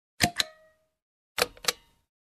На этой странице собраны звуки кнопок меню — короткие и четкие аудиофрагменты, подходящие для приложений, сайтов и игр.
7. Переключающая кнопка